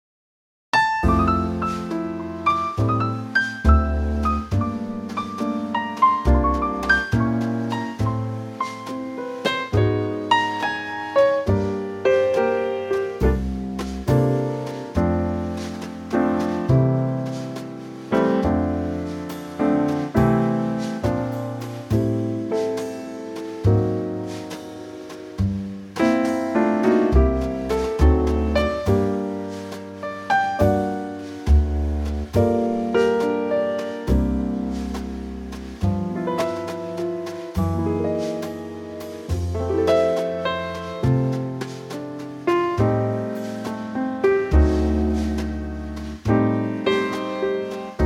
Unique Backing Tracks
key - F - vocal range - C to Eb
Lovely old 40's standard in a gorgeous Trio arrangement.